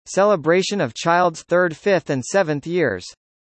「celebration of child’s third fifth and seventh years」の英語発音はこちら▼